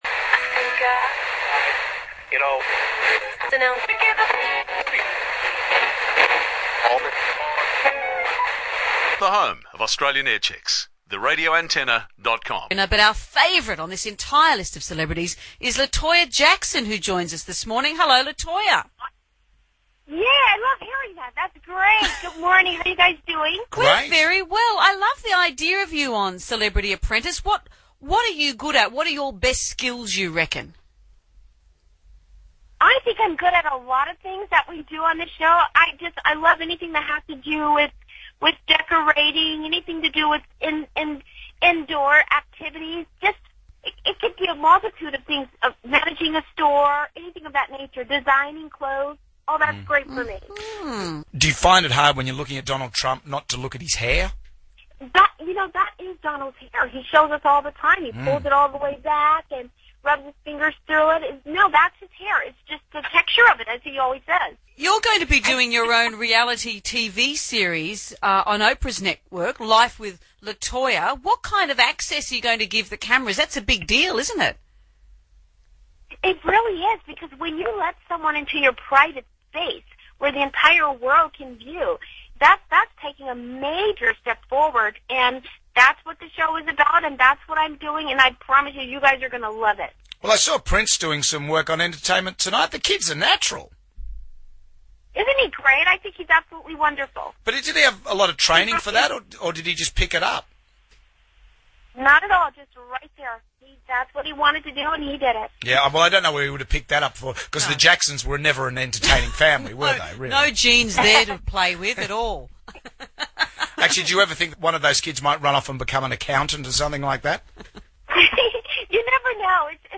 RA Aircheck – WSFM Jonesy and Amanda La Toya interview
RA-Aircheck-WSFM-Jonesy-and-Amanda-La-Toya-interview.mp3